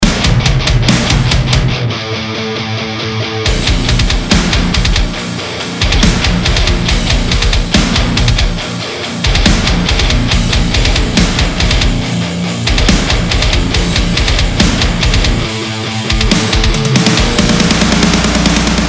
Ibanez TS808 - Metal TEST